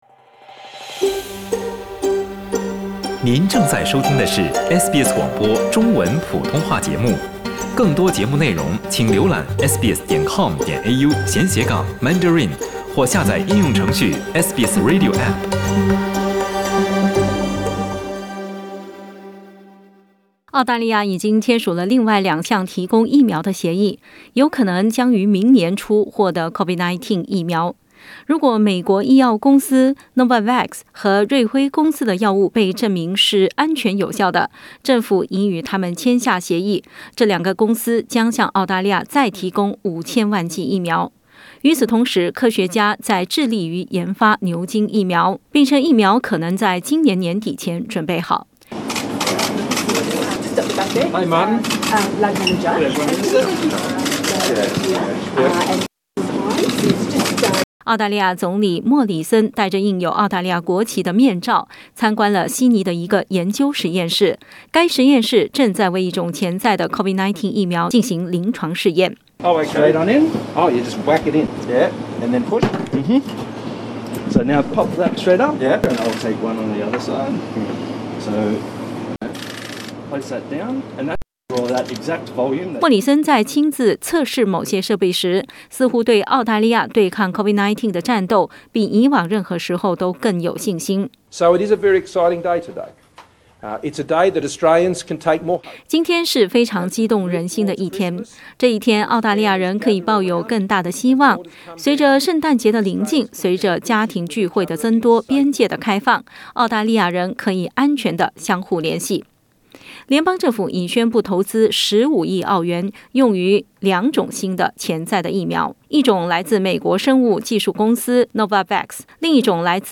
（请听报道） 澳大利亚人必须与他人保持至少 1.5 米的社交距离，请查看您所在州或领地的最新社交限制措施。